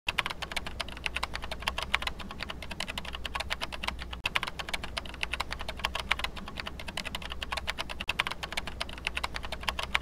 Keyboard Sound Effect Free Download
Keyboard